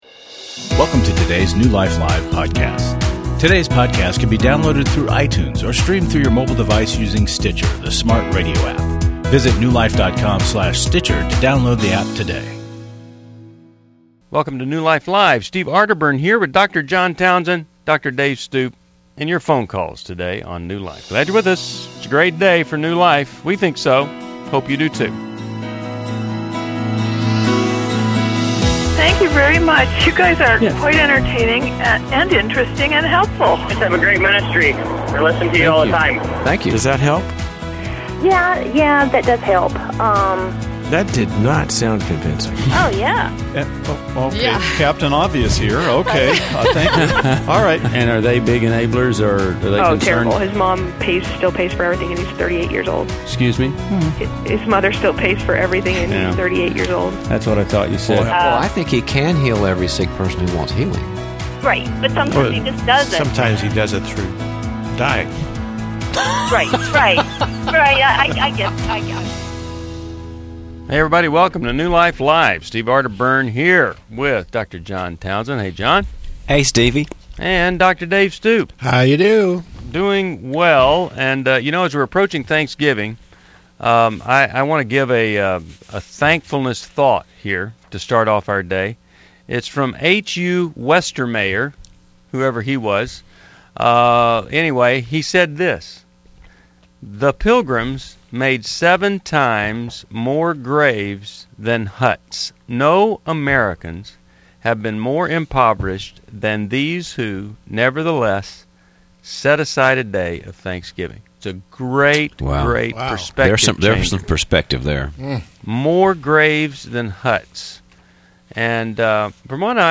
Explore relationship healing, forgiveness, and parenting challenges in New Life Live: November 15, 2012, as experts tackle real caller concerns.